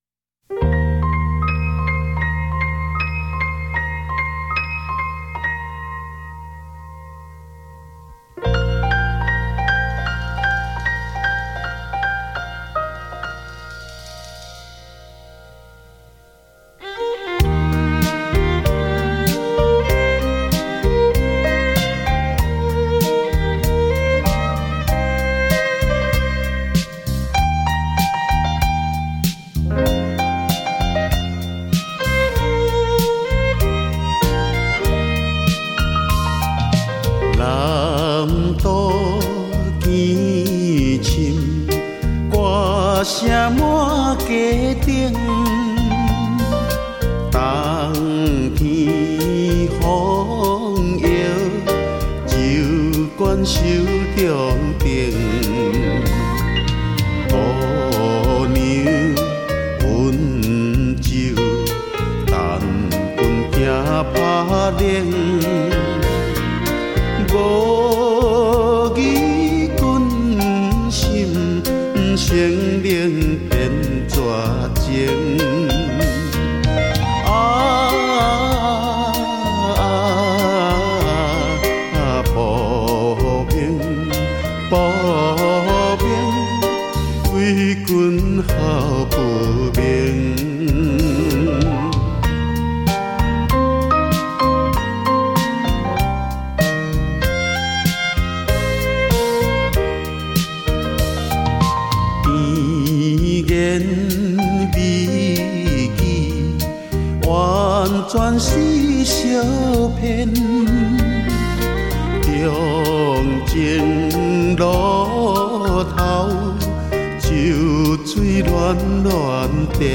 PIANO BAR